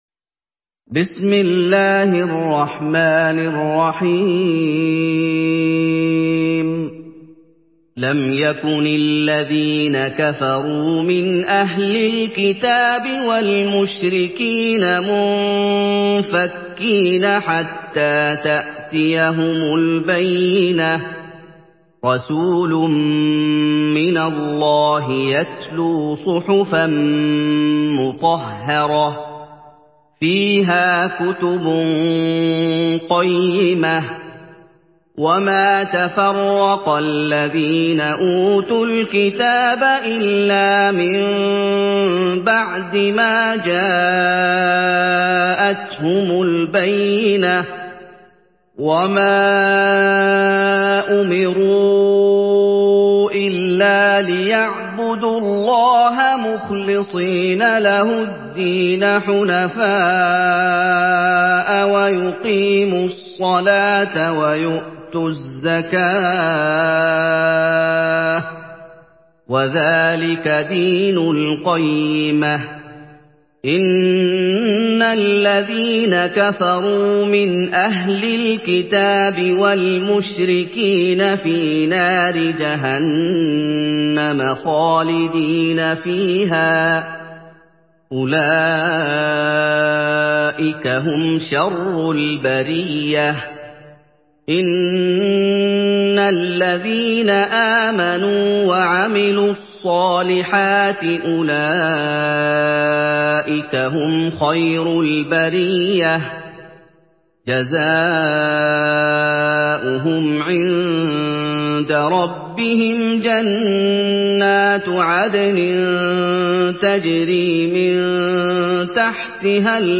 Surah আল-বায়্যিনাহ্ MP3 in the Voice of Muhammad Ayoub in Hafs Narration
Murattal Hafs An Asim